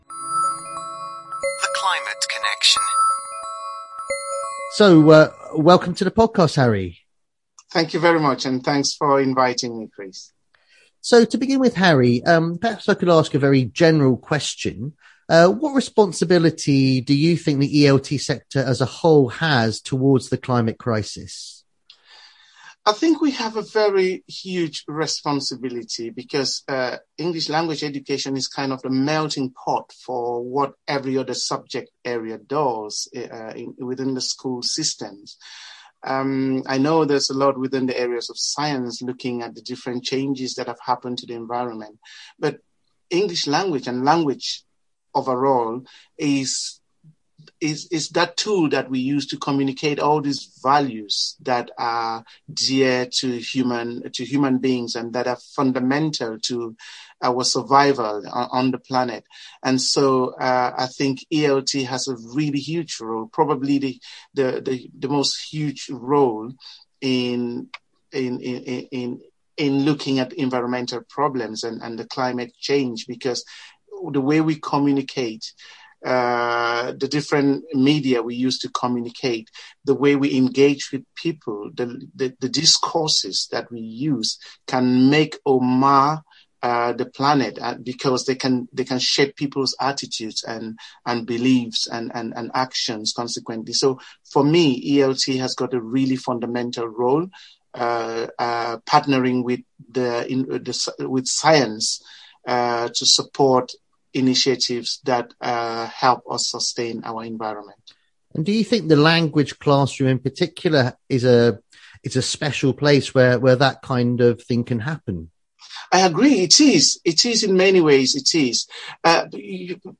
Across the ten episodes, we’ll hear from a wide range of leading practitioners working in the sector – teachers, trainers, researchers, publishers and authors.